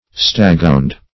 Staghound \Stag"hound`\ (-hound`), n. (Zool.)